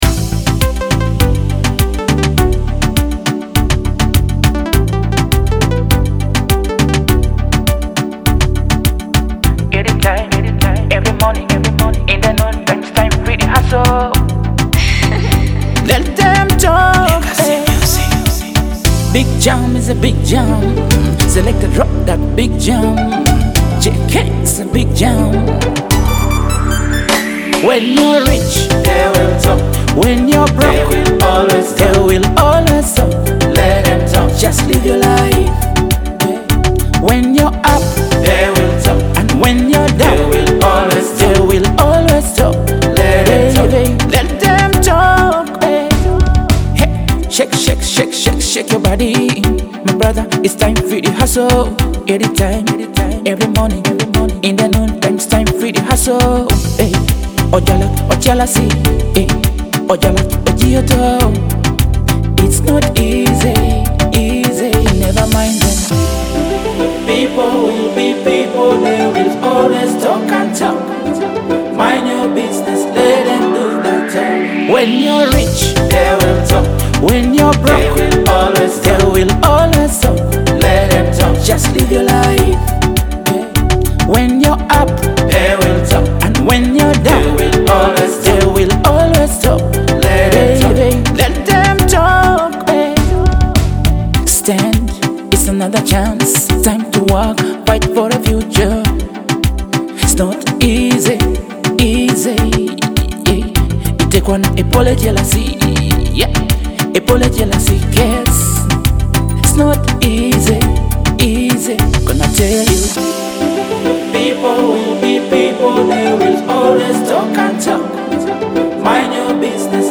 a sizzling dancehall track that’s perfect for parties
Known for its infectious rhythm and catchy lyrics
blending vibrant beats with smooth vocals.